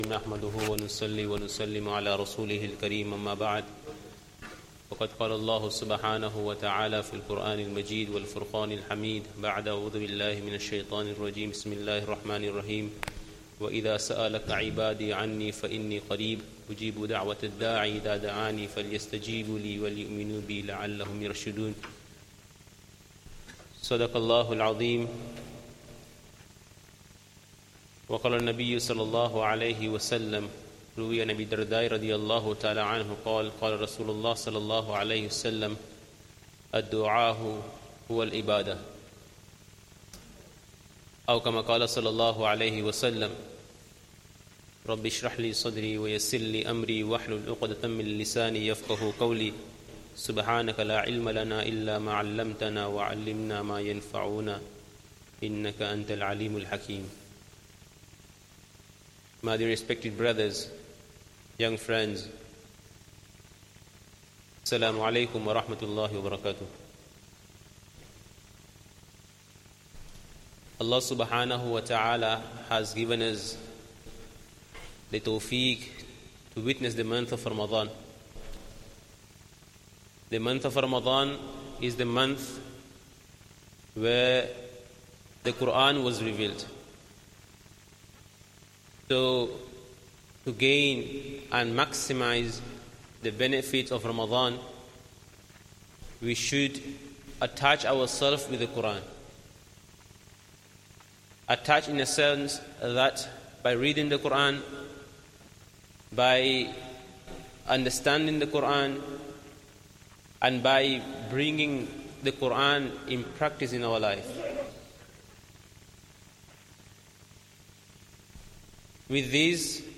Jummah Talk and 2nd Khutbah